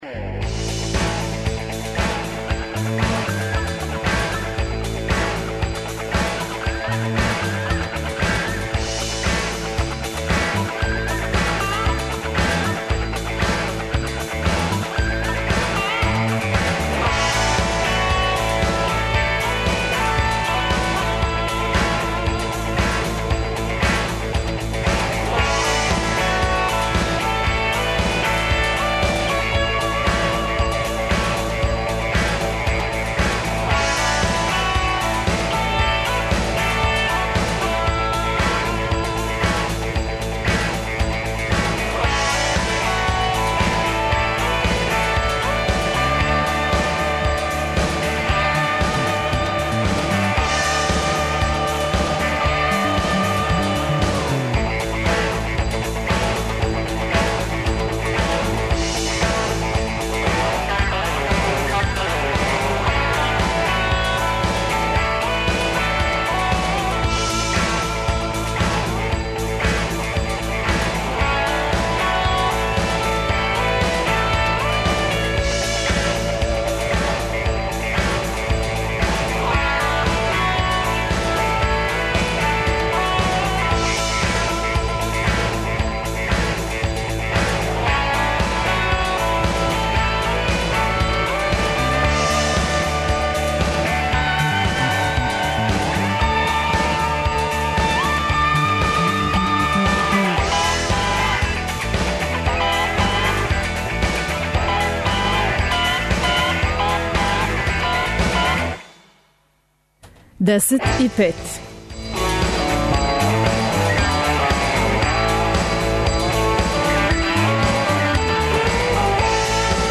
бројни координатори и волонтери-учесници акције "Очистимо Србију"